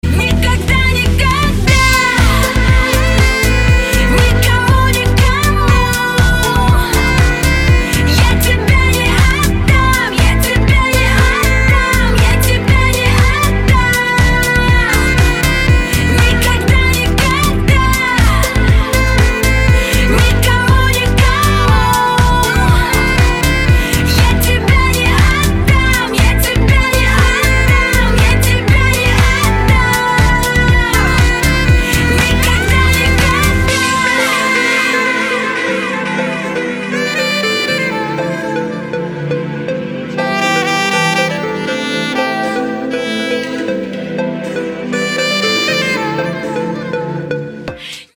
• Качество: 320, Stereo
женский вокал
remix
deep house
dance
Саксофон